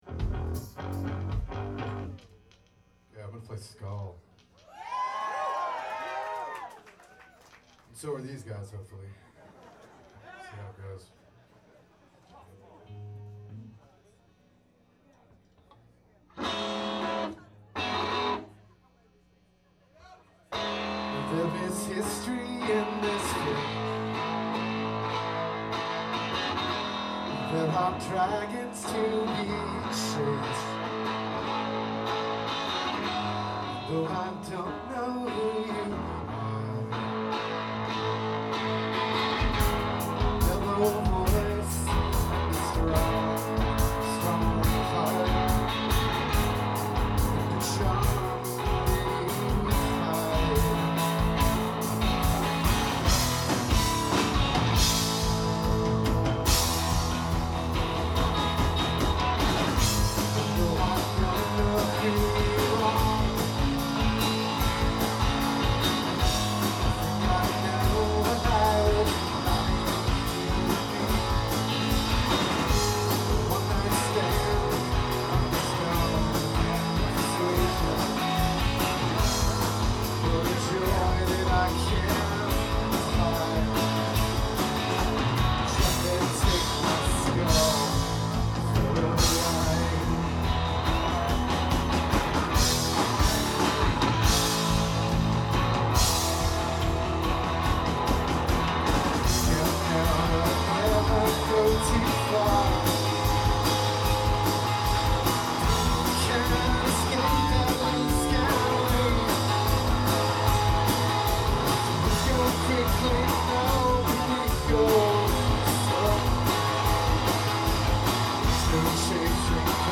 This was the first recording with my new TASCAM DR-100.